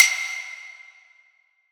normal-hitfinish.ogg